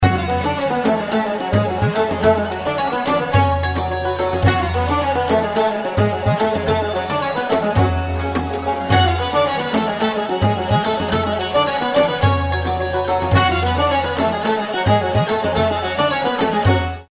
رینگتون بی کلام و شاد